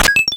DoorUnlock.wav